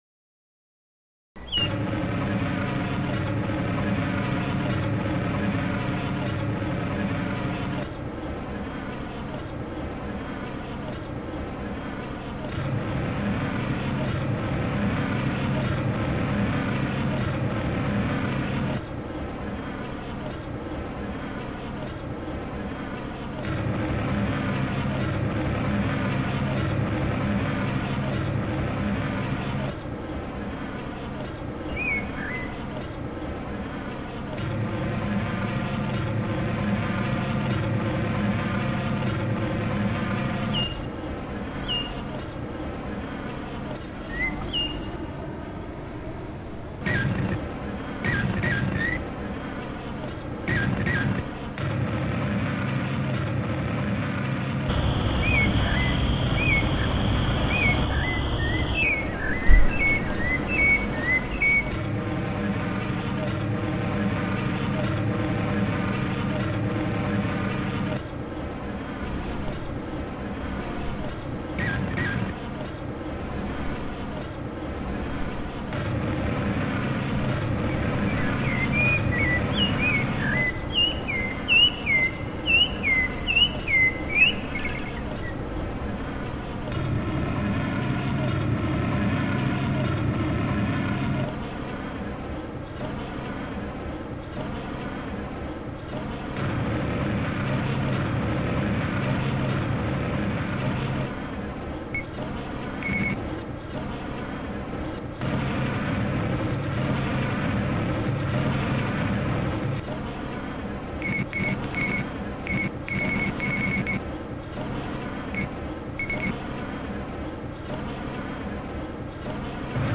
Reimagined sound from Argentina
I was surprised to find the base rhythm inside a faint background sound, and am impressed with the liquidity of the birdsong (I'm told it's a zorzal, quite different from birdsongs in Northern Canada). My remix exclusively uses tones, notes, and textures that are present in the original field recording.